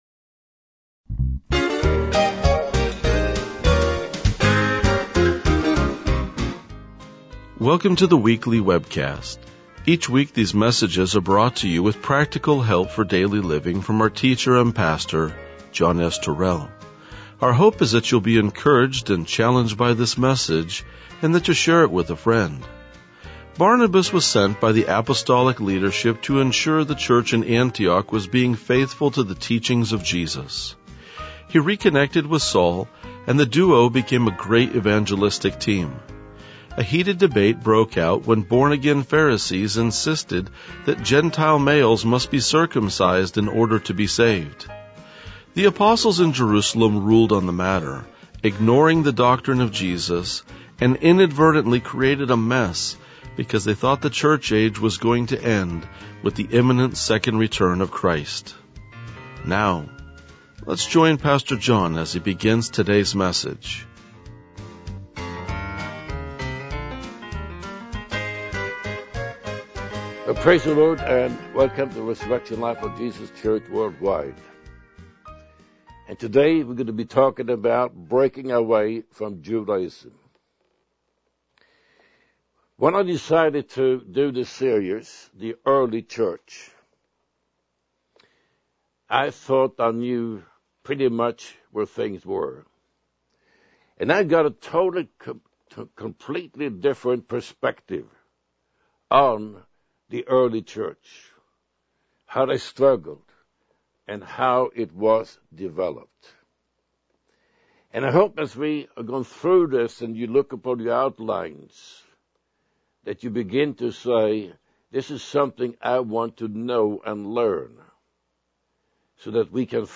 RLJ-2008-Sermon.mp3